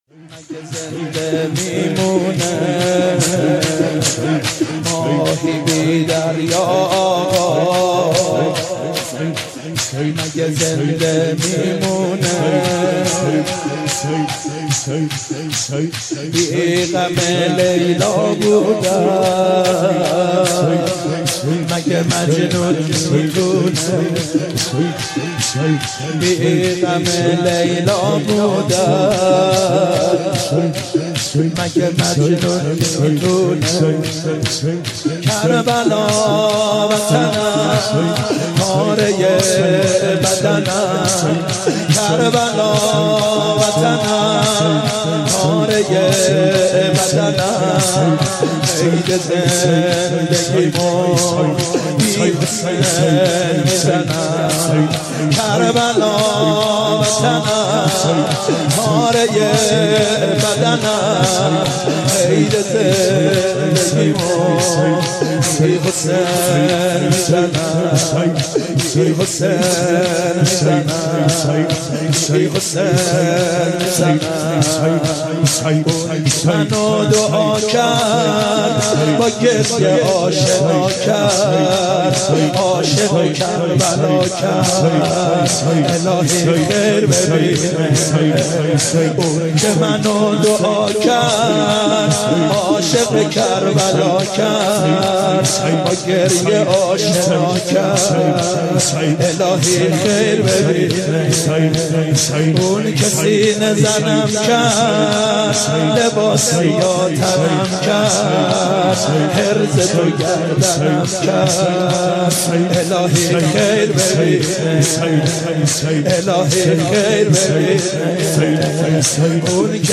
مداح
قالب : شور